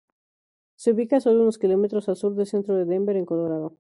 Co‧lo‧ra‧do
/koloˈɾado/